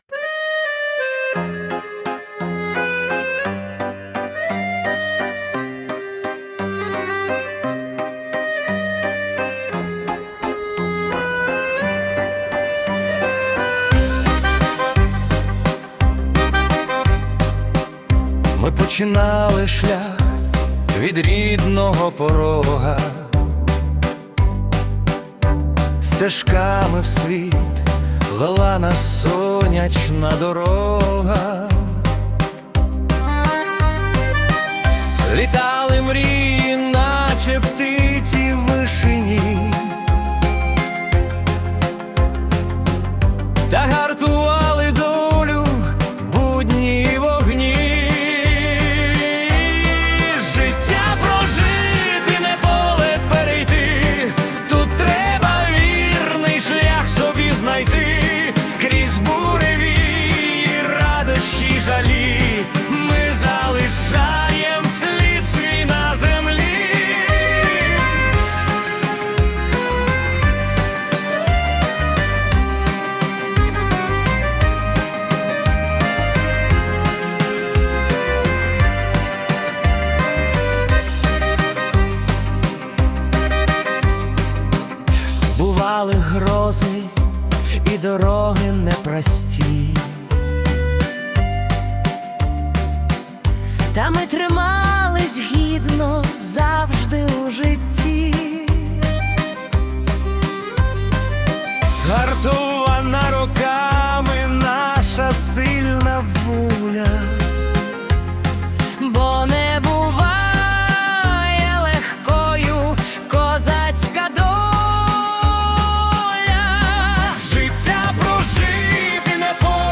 🎵 Жанр: Український вальс / Ретро
Вальс життєвого шляху